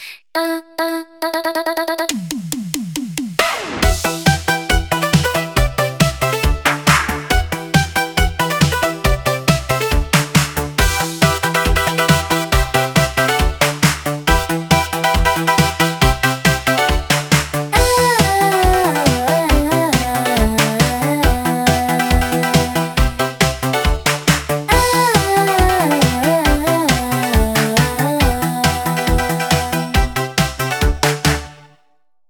実際に「80年代っぽいテクノポップを作って」というプロンプトで試してみたところ、ボコーダー風な歌声や、シンセサイザーのキラキラした音色やリズムマシン的なビートが特徴的な楽曲が生成されました。